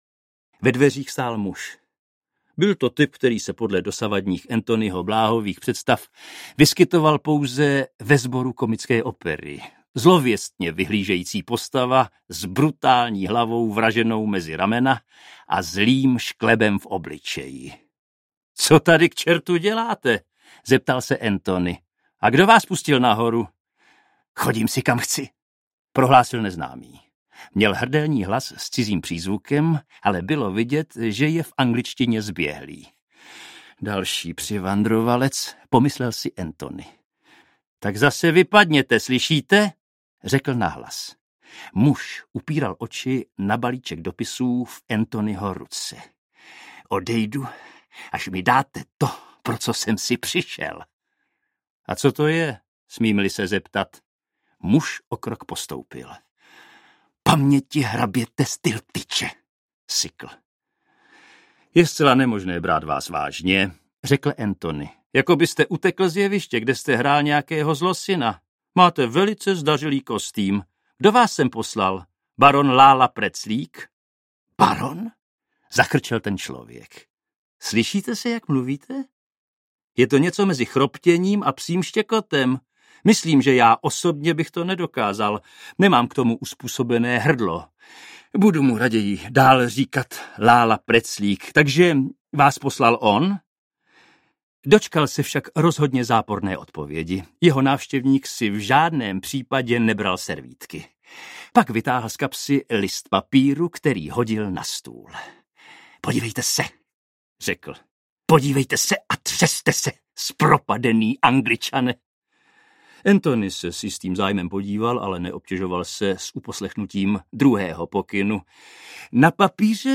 Tajemství Chimneys audiokniha
Ukázka z knihy
Vyrobilo studio Soundguru.